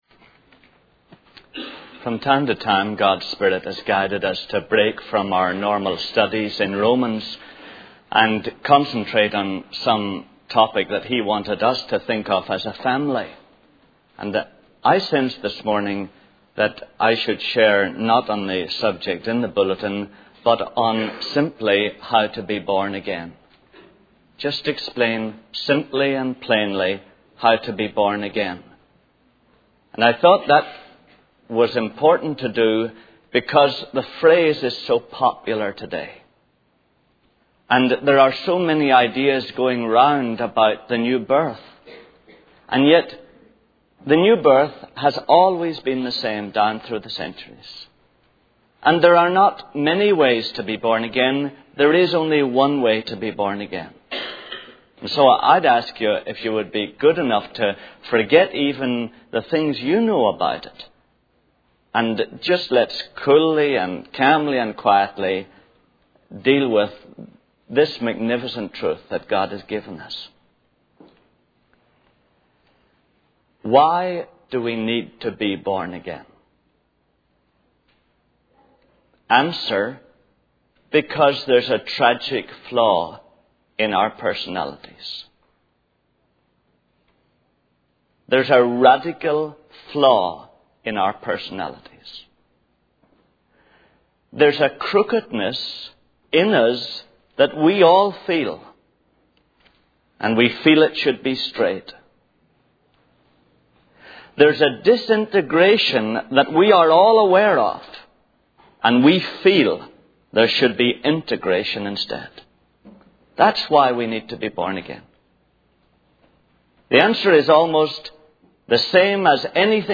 In this sermon, the speaker reflects on a lonely evening spent at home.